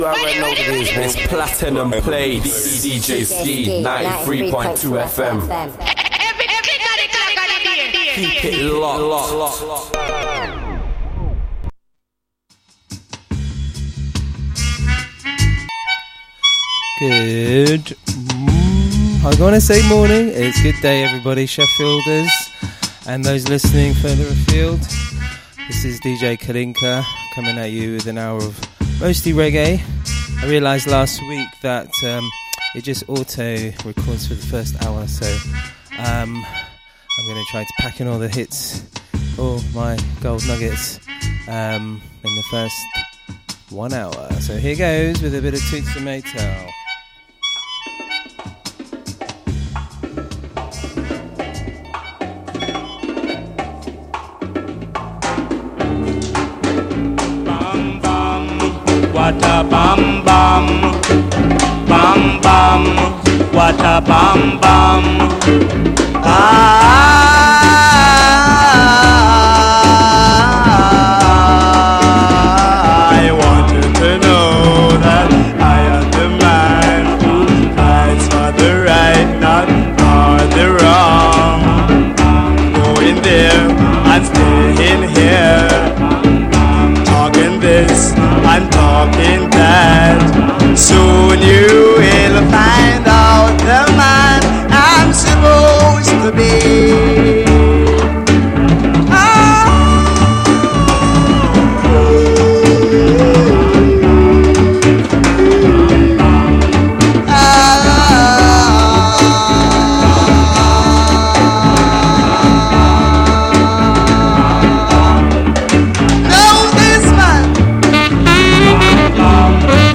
Platinum Plates will re-introduce you to tracks and artist profiles/updates and will re-ignite the light for all the revival Reggae, Rockers, Conscious lyrics, Lovers, Ska and Version fanatics out there.